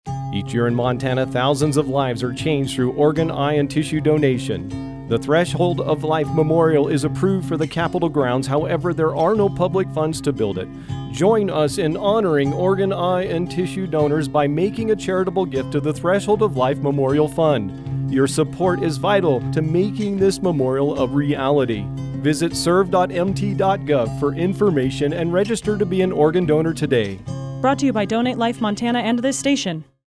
Public Service Announcements
Radio Spots